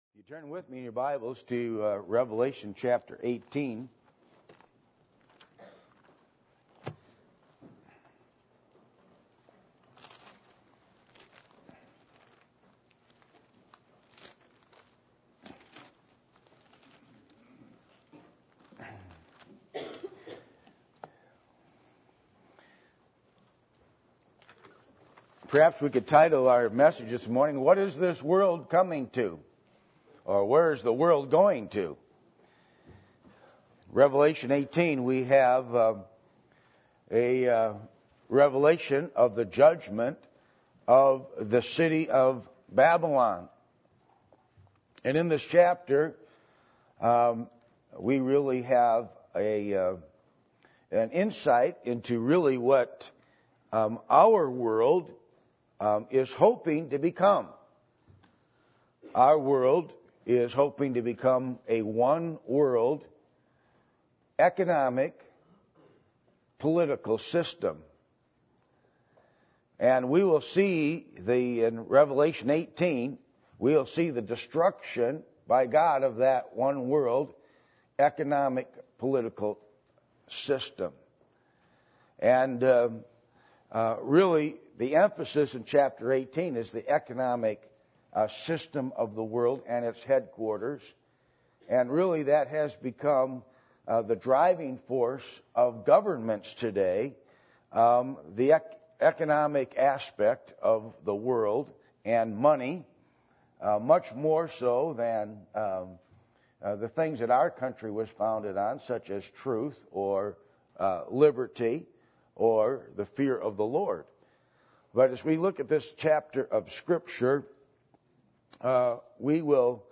Passage: Revelation 18:1-24 Service Type: Sunday Morning %todo_render% « How To Interpret The Bible